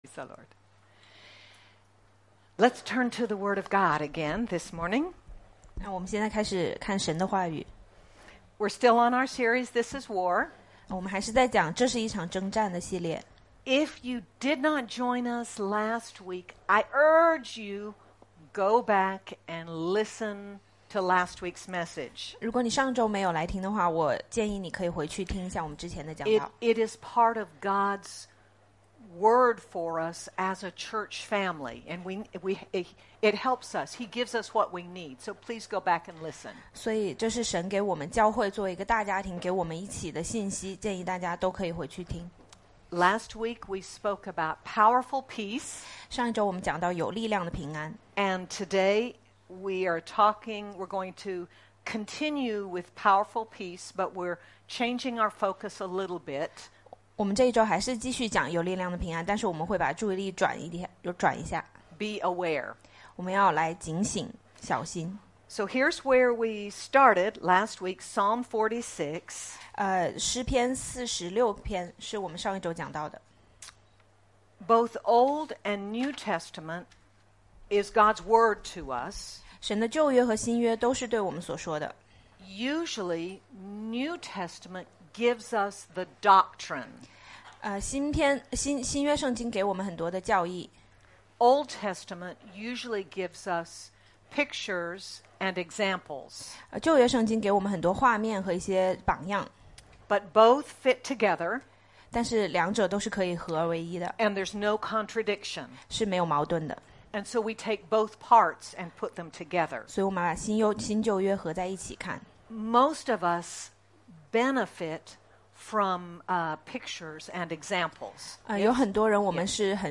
We must be aware of how the enemy attacks us, but even more, we must be aware of how we stand and fight with the weapons our God gives us to overcome. Sermon By